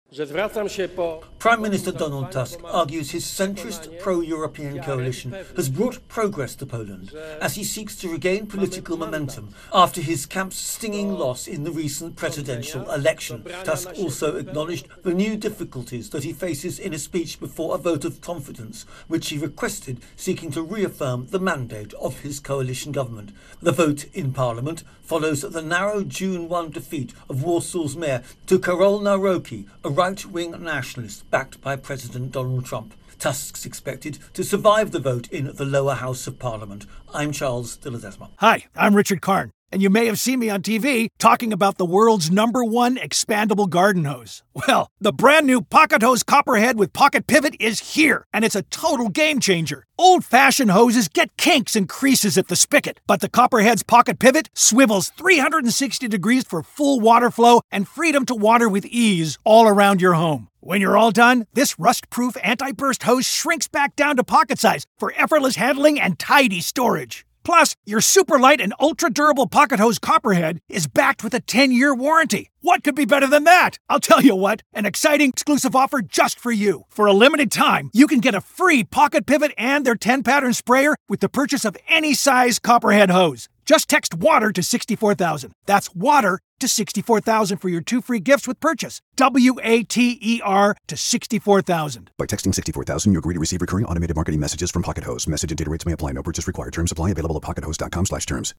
Polish Prime Minister Donald Tusk makes his case before a confidence vote in the Polsh parliament.